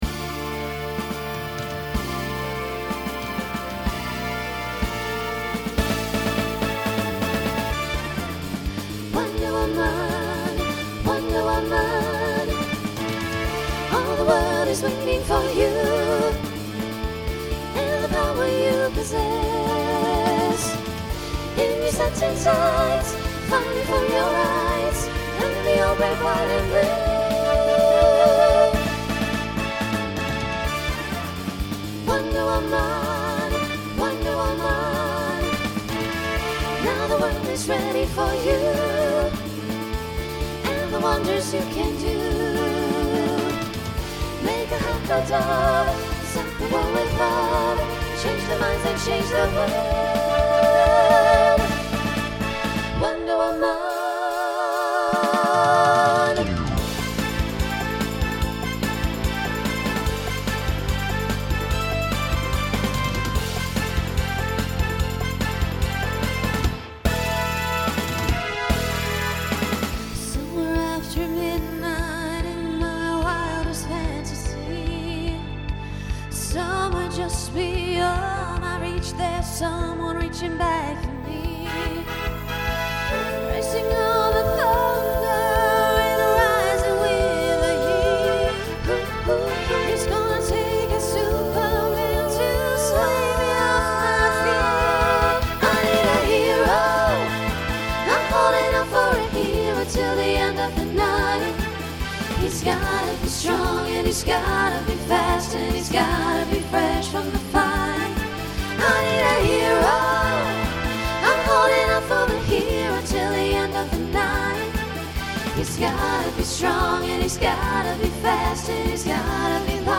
Voicing SSA
Genre Pop/Dance